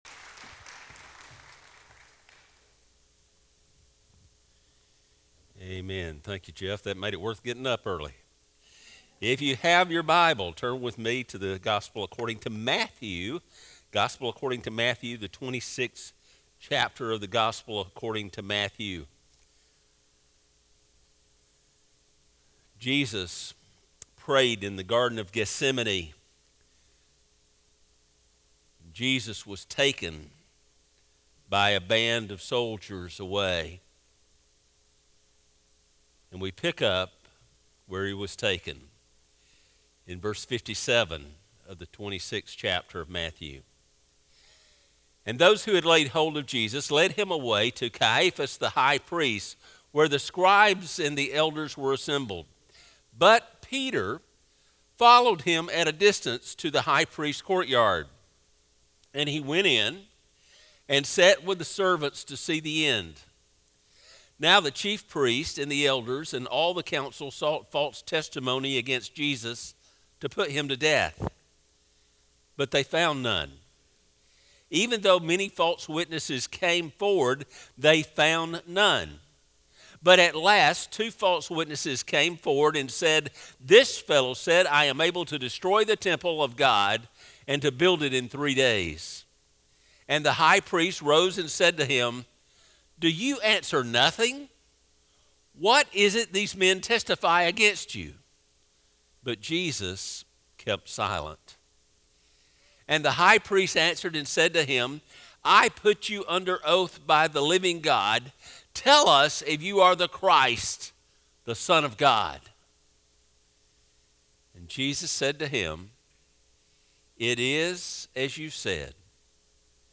Sermons | Northside Baptist Church